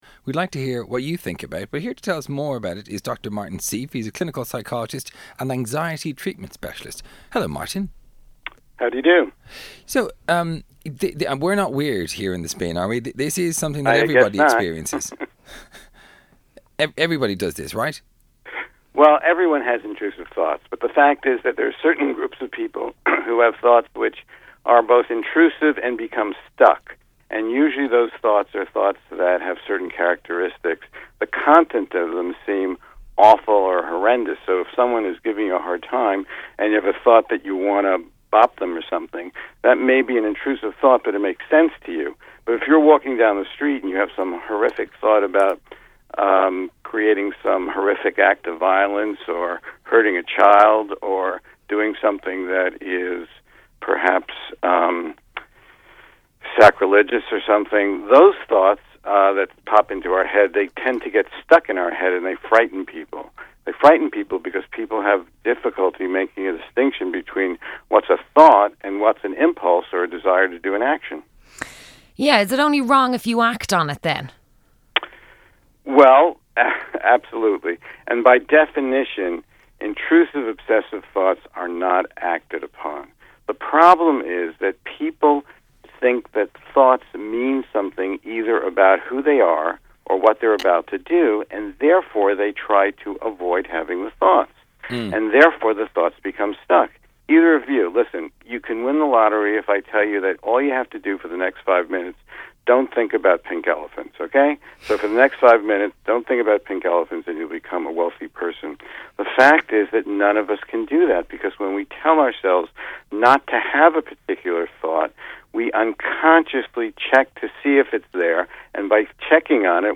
Radio Interview on a Dublin Radio Station